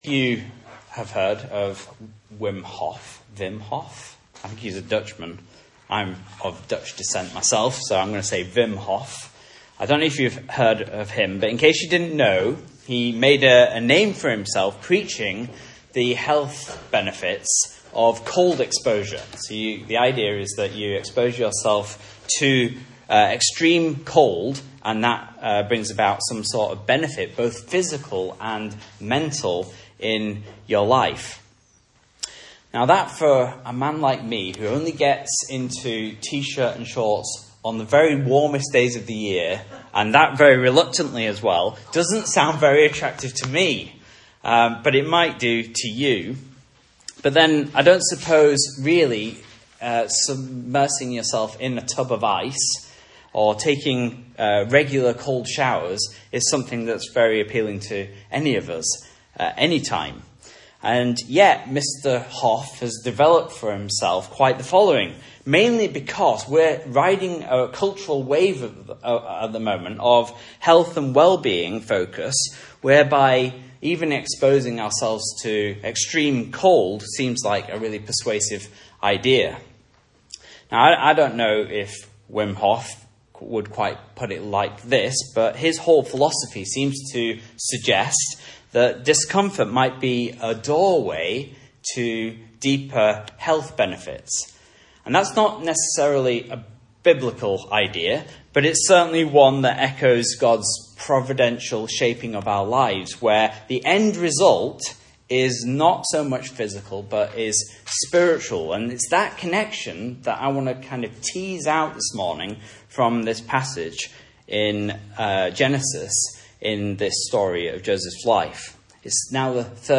Message Scripture: Genesis 42 | Listen